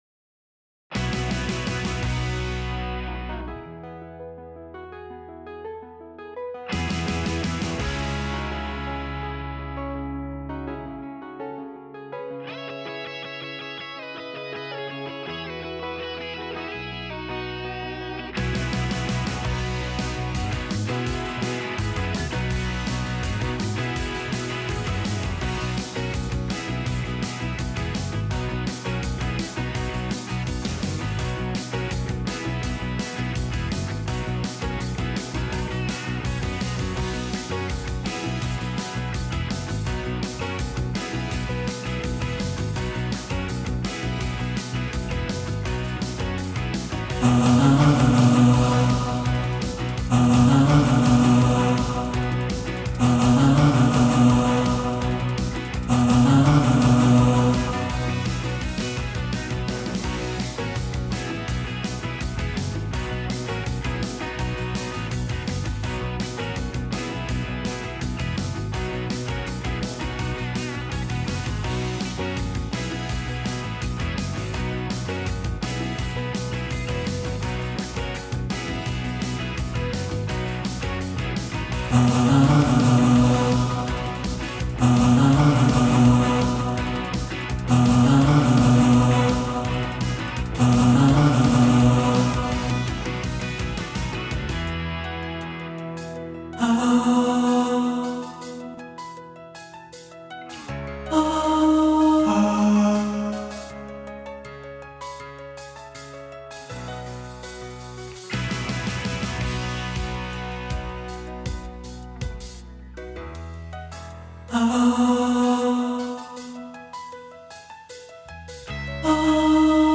Hole-In-Your-Soul-Baritone.mp3